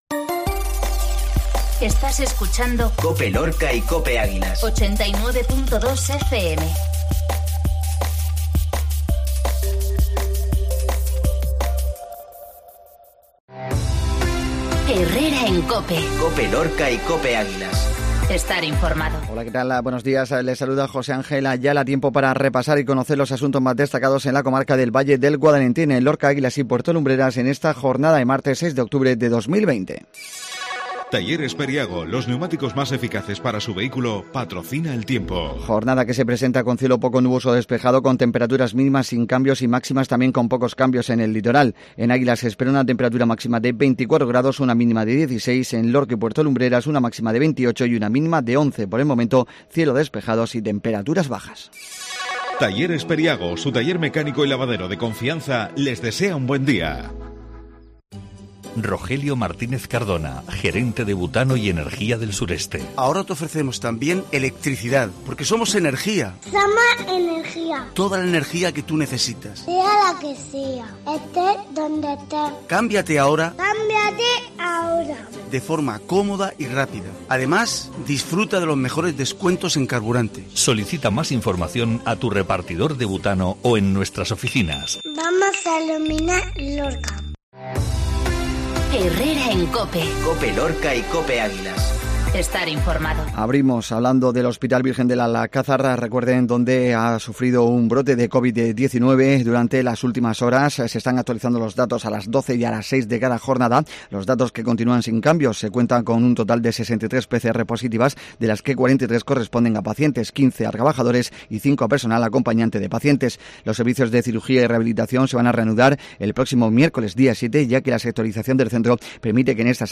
INFORMATIVO MATINAL MARTES 825